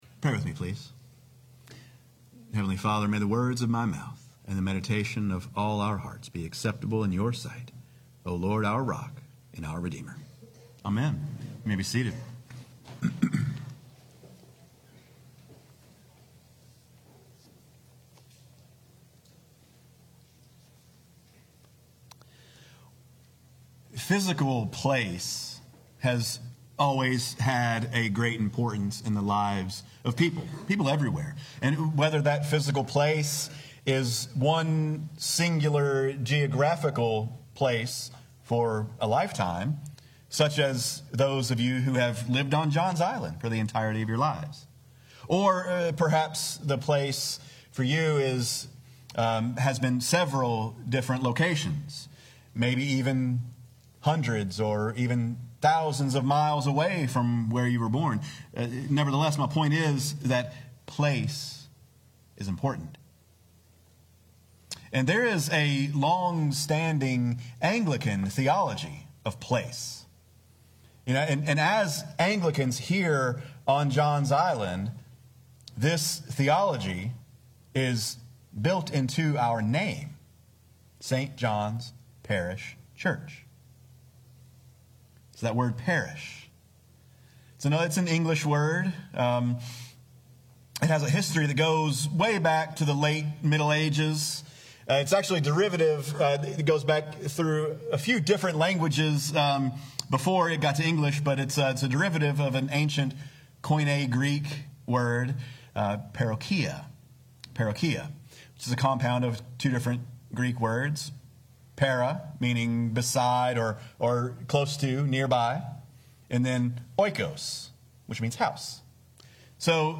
Sermons | St. John's Parish Church